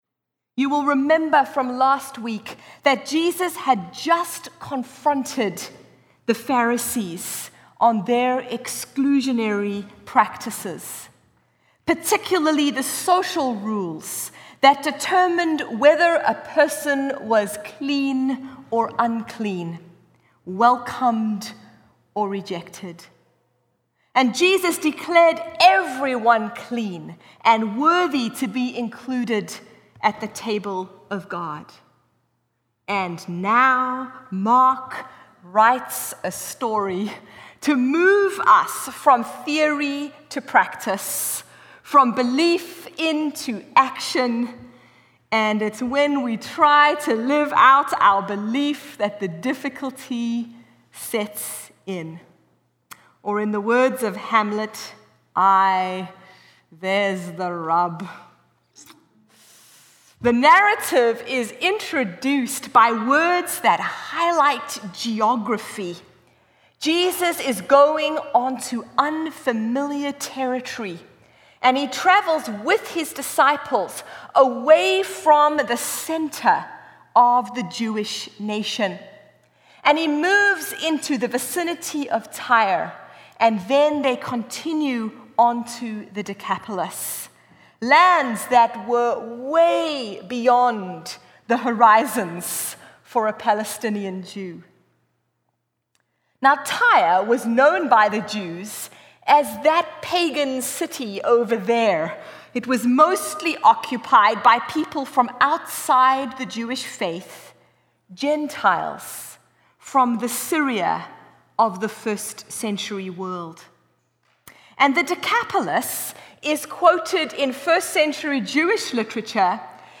Sixteenth Sunday after Pentecost 2018